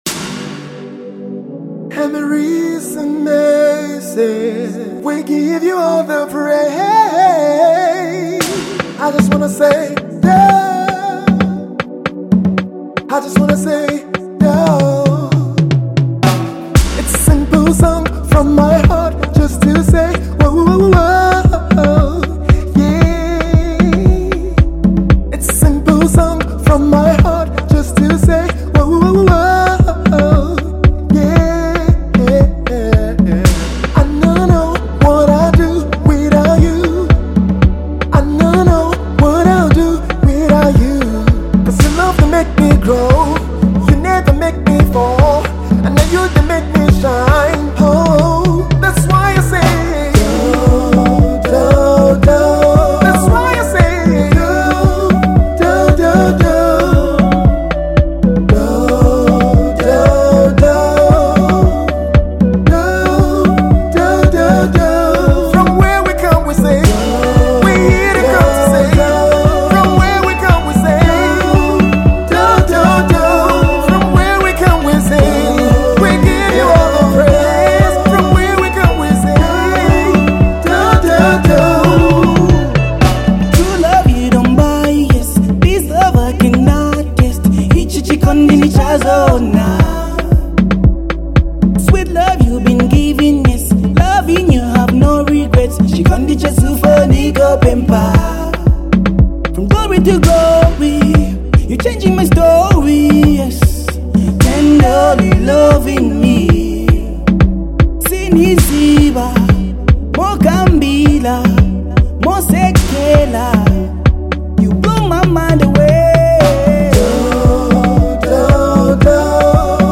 Thanksgiving song
a groovy beat